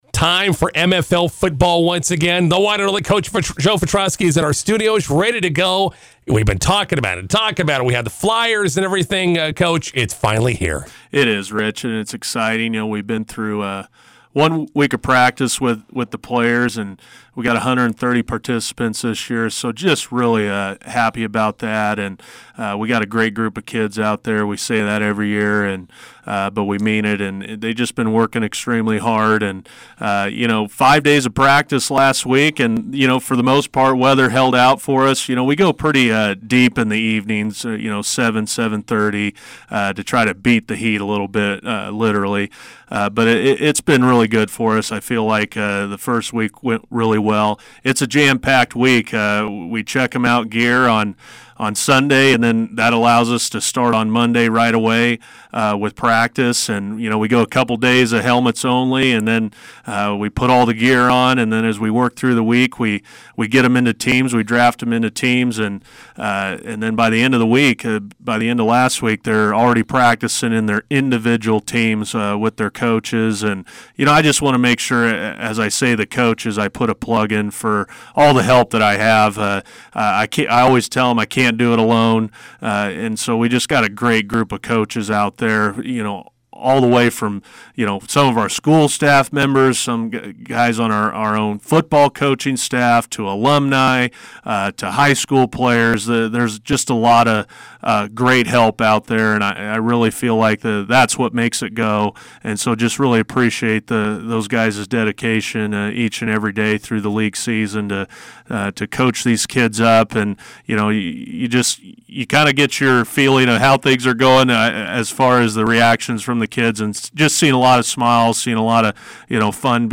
INTERVIEW: McCook Football League games kick off on Wednesday at Weiland Field.